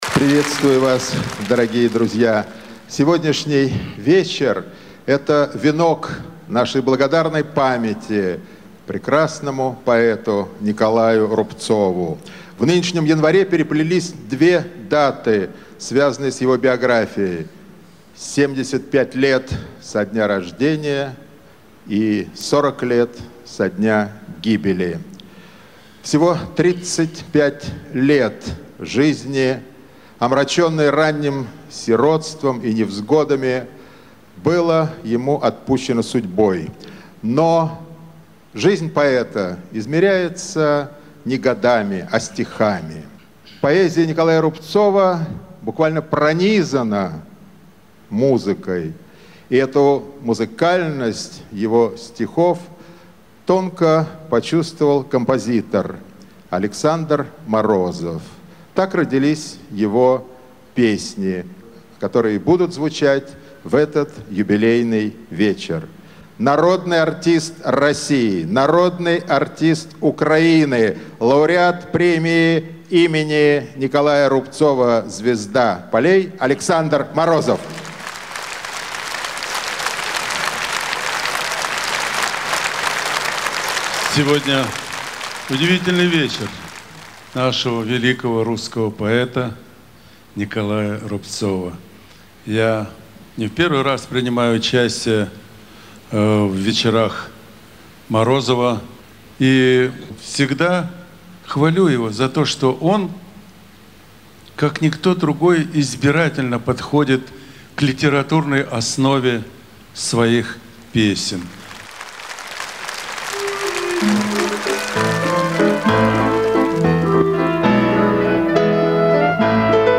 Концерт начала 2011 года, целиком повященного творчеству Николая Рубцова в честь
Во вступительном слове голос Святослава Белзы – широко известного музыкально-литературного профессионала и ведущего многих радио и телепередач
голос Иосифа Кобзона.